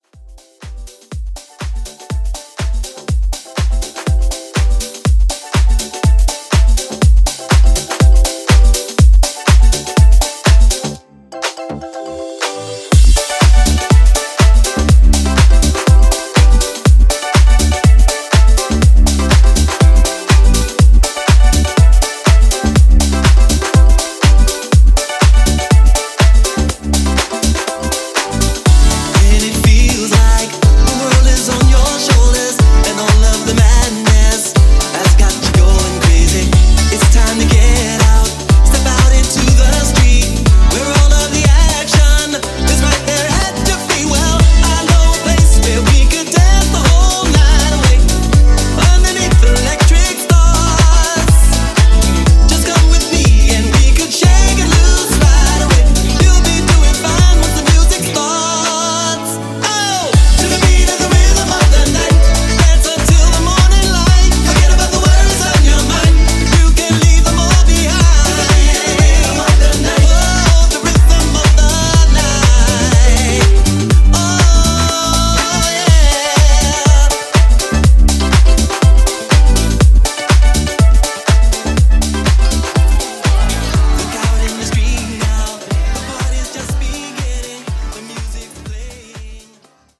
Genre: DANCE
Clean BPM: 122 Time